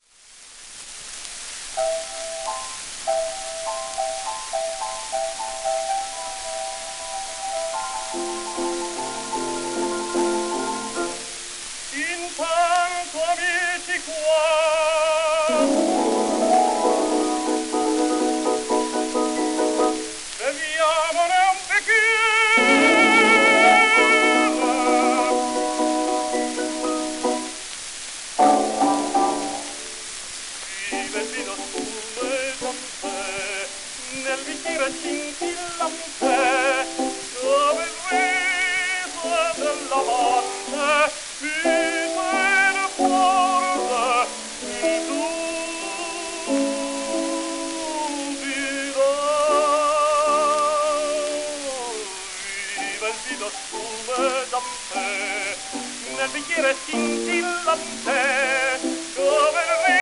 w/ピアノ
10インチ片面盤
盤質A- *スレ、小キズ 、サーフェイスノイズ
1905年のN.Y録音